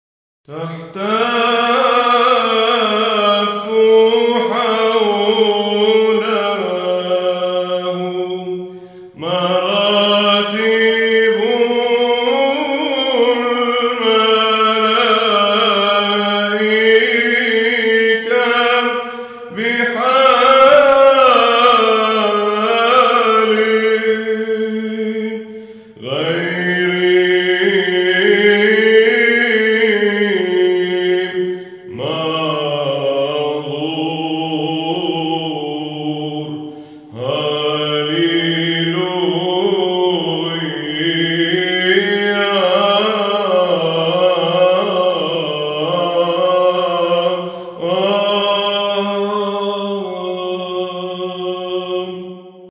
15- الشيروبيكون باللّحن الرابع